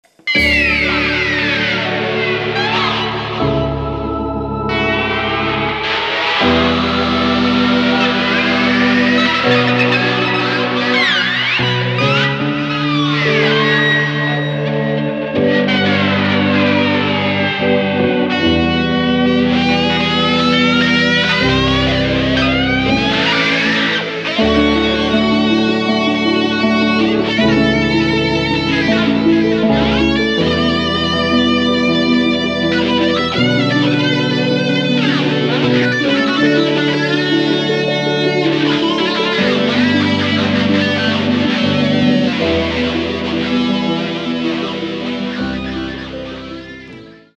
Психоделия
клавиши
гитара
Черновой набросок=) Стого не судите...